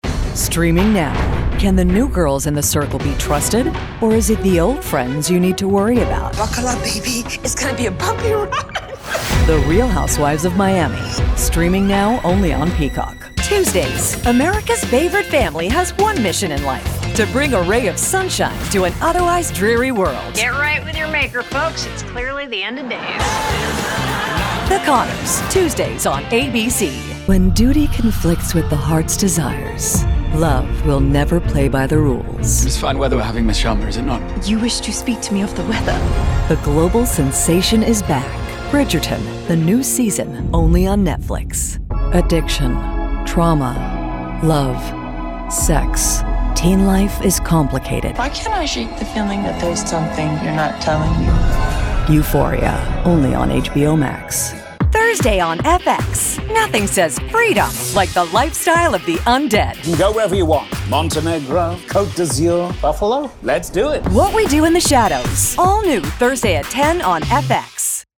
Female Voice Over, Dan Wachs Talent Agency.
Expressive, Thoughtful, Versatile
Network Promo